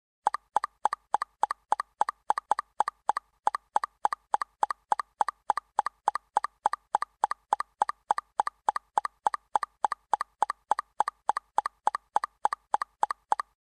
Son du nouveau message pour vk